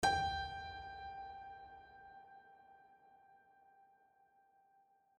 piano-sounds-dev